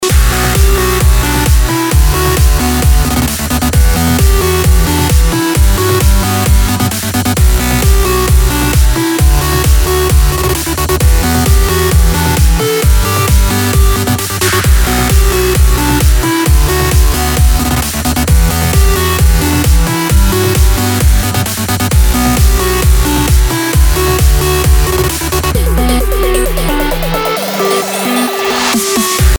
Вот эти транс-гейты в конце квадратов?
А надо, чтобы можно было разные длины заглушения выставлять (если вслушаться внимательно в пример, то слышно, что стартует гейт с 1/64 и завершается 1/32).